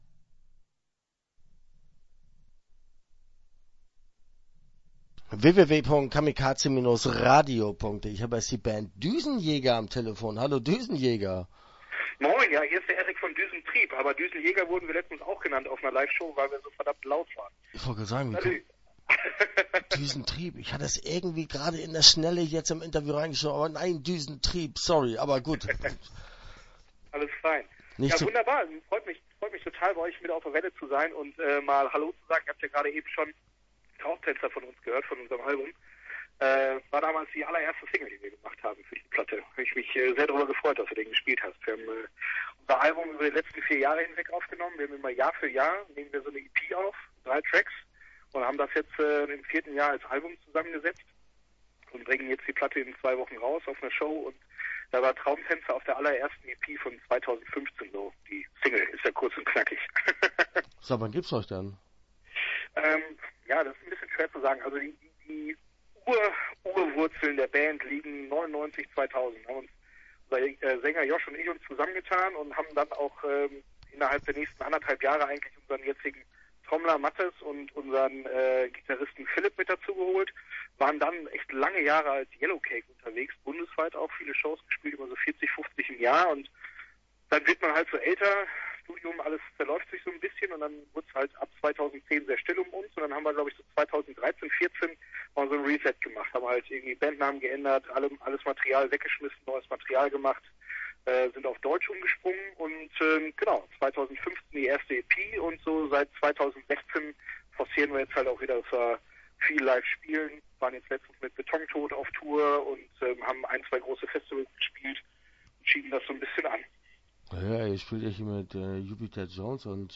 Düsentrieb - Interview Teil 1 (12:44)